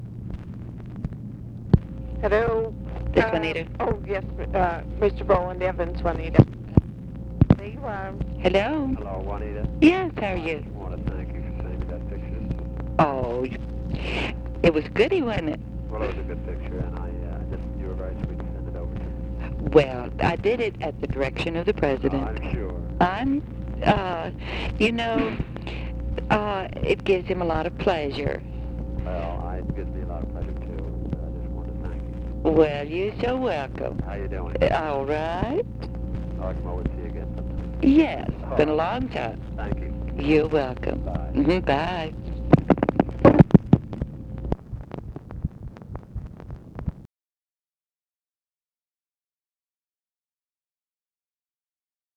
Conversation with ROWLAND EVANS, August 31, 1966
Secret White House Tapes